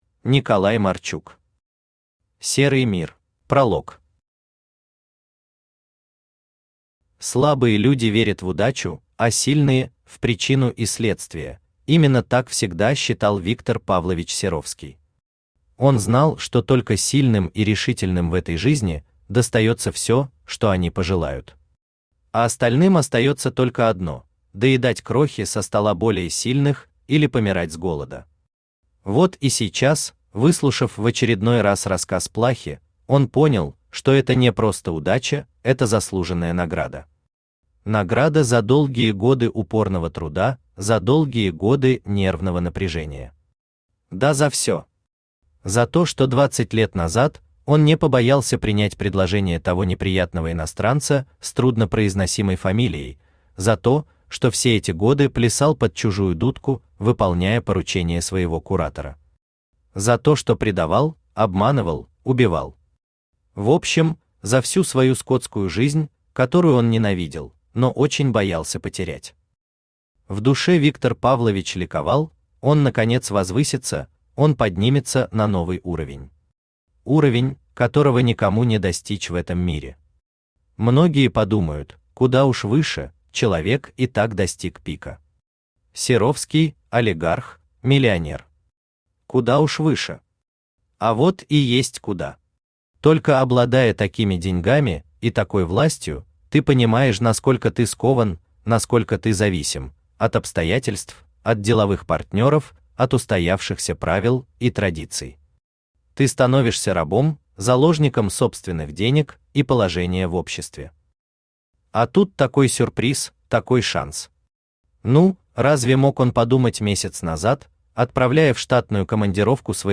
Aудиокнига Серый мир Автор Николай Марчук Читает аудиокнигу Авточтец ЛитРес.